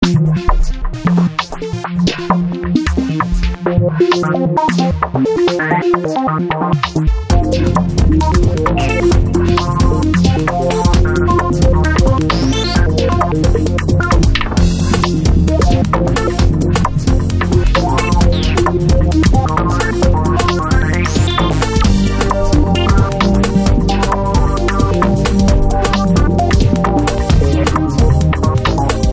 Background Music
While all of this is happening, I play some groovy background music to set the mood.